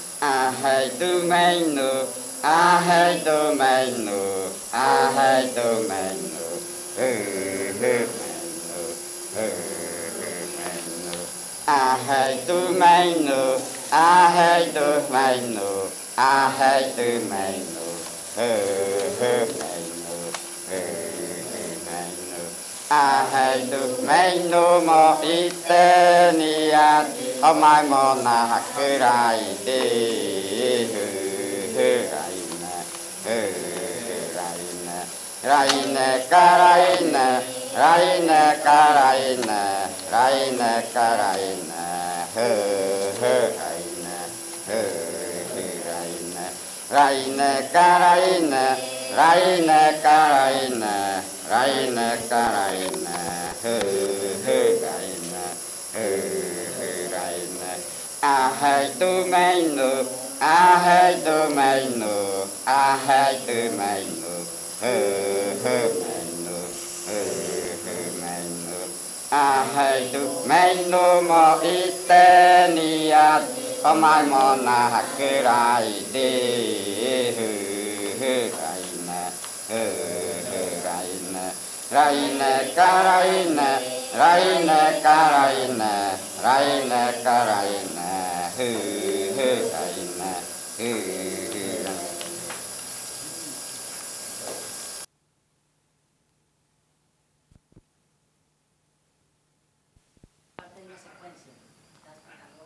Canto de la variante jaiokɨ
Canto del ritual de frutas yuakɨ del pueblo murui.
Song from the yuakɨ fruit ritual, jaiokɨ variant, of the Murui people.